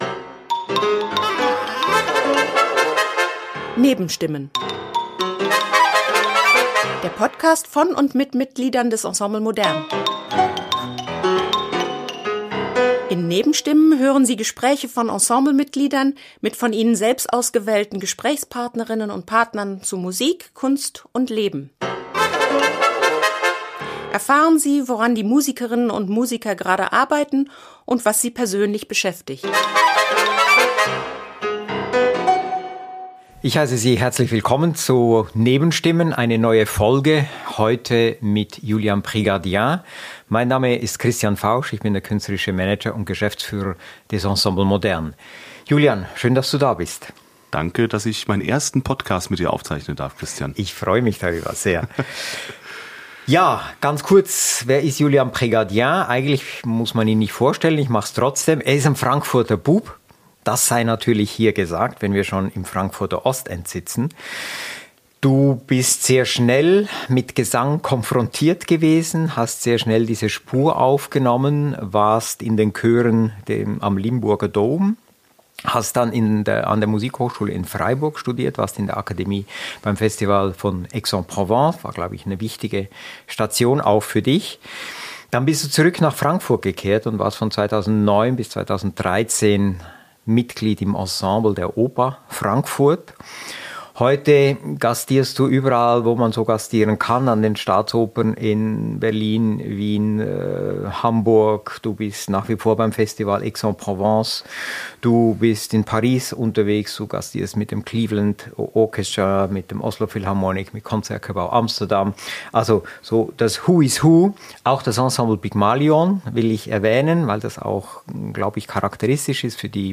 In "Nebenstimmen" hören Sie Gespräche von Ensemble-Mitgliedern mit von ihnen selbst ausgewählten Gesprächspartnerinnen und -partnern zu Musik, Kunst und Leben.